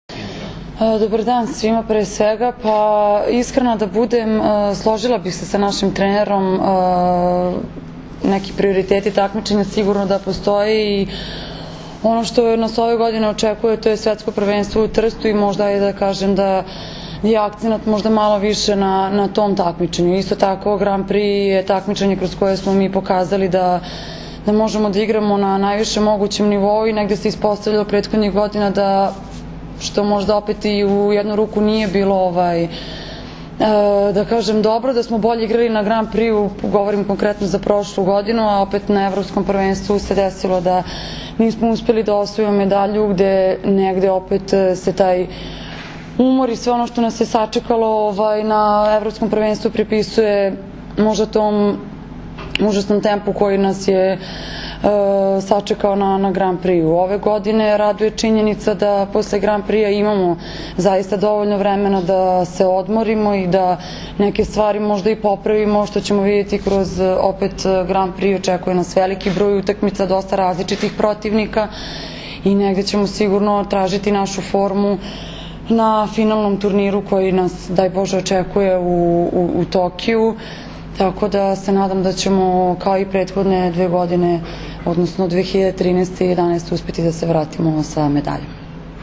Tim povodom, danas je u beogradskom hotelu “M” održana konferencija za novinare, kojoj su prisustvovali Zoran Terzić, Maja Ognjenović, Jelena Nikolić i Milena Rašić.
IZJAVA MAJE OGNJENOVIĆ